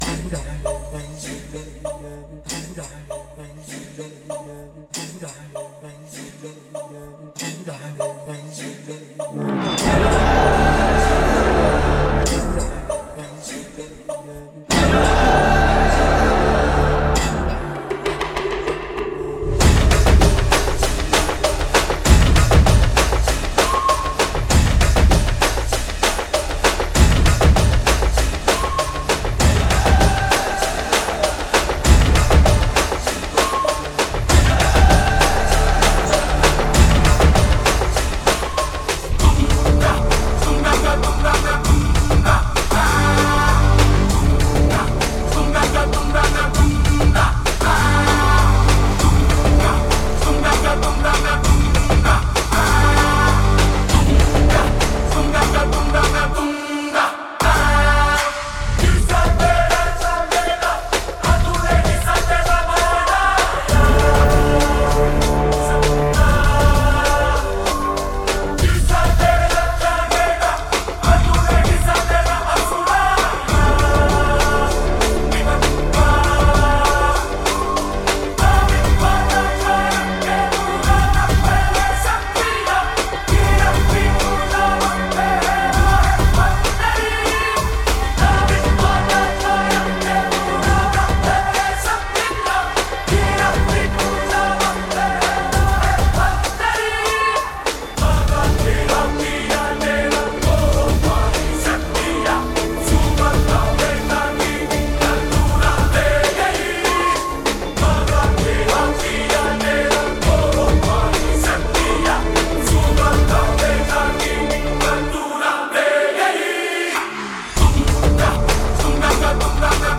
without dialogues and fight sounds